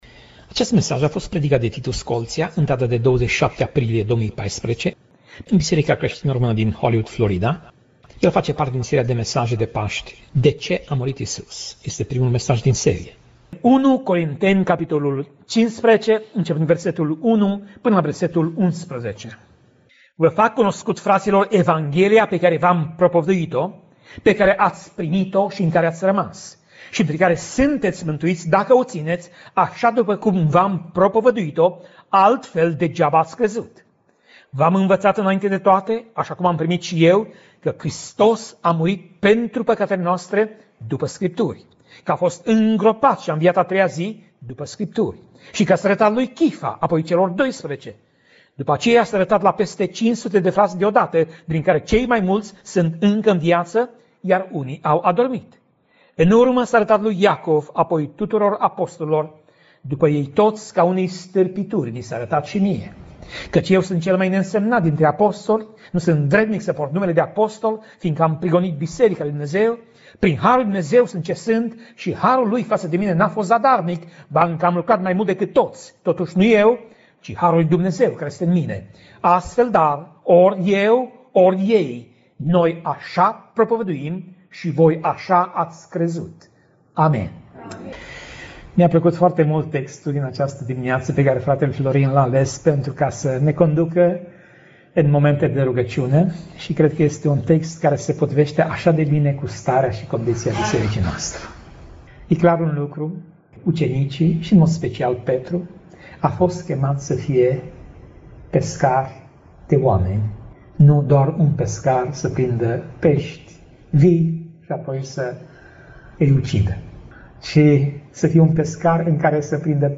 Pasaj Biblie: 1 Corinteni 15:1 - 1 Corinteni 15:11 Tip Mesaj: Predica